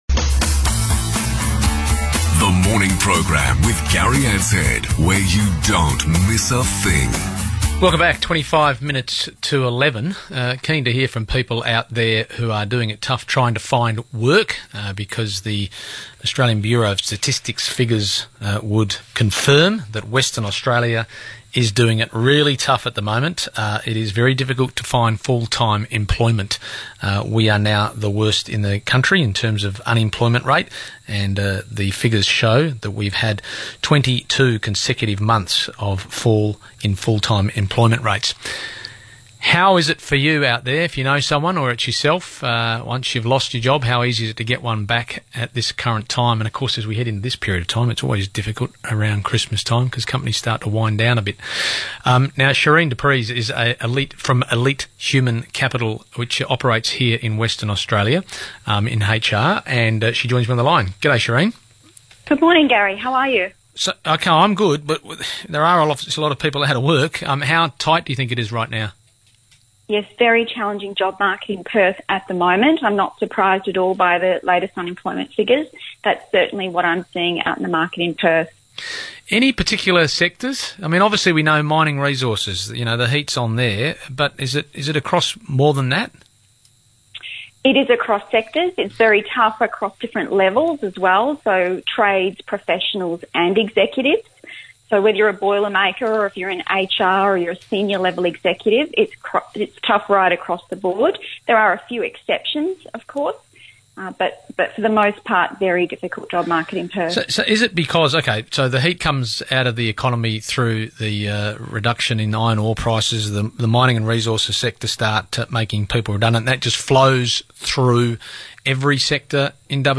Listen to an interview on 6PR  about the latest unemployment figures and current state of the job market in Western Australia.